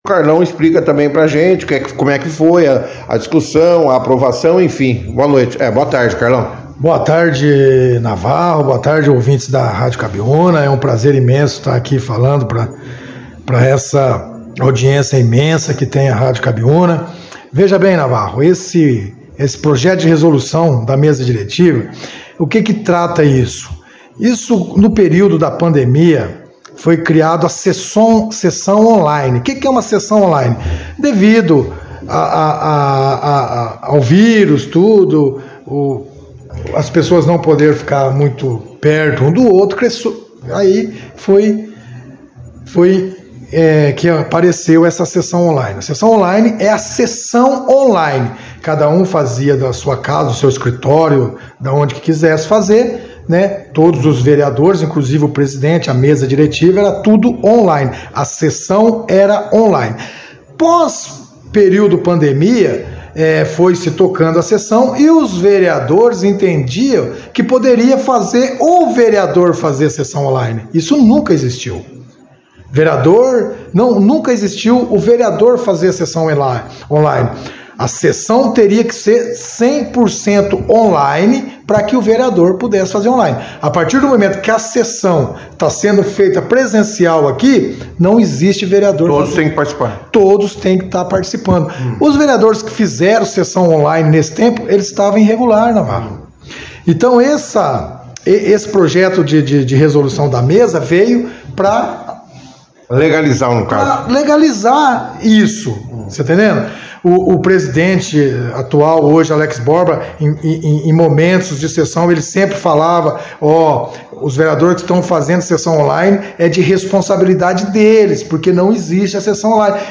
A sessão ganhou destaque na 2ª edição do jornal “Operação Cidade” desta terça-feira, dia 30 de abril, com a participação dos vereadores Carlão Demicio e Mano Viera, que falaram sobre a sessão.